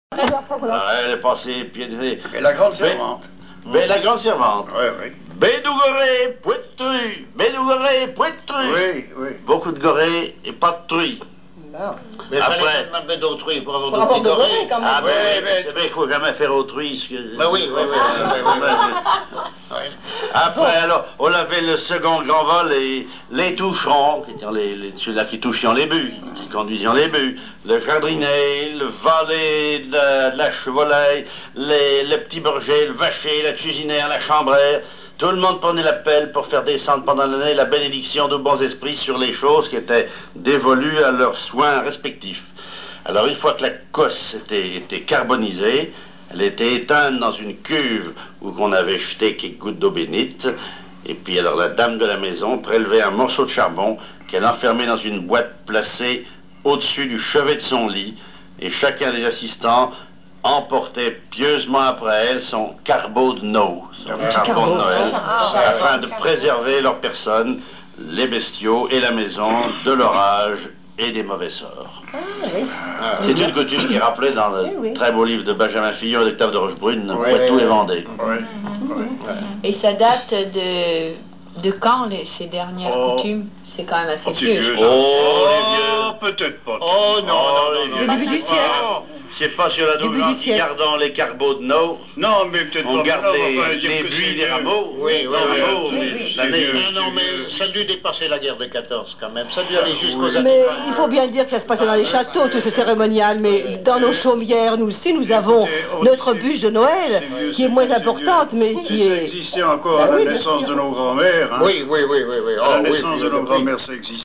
émission La fin de la Rabinaïe sur Alouette
Catégorie Témoignage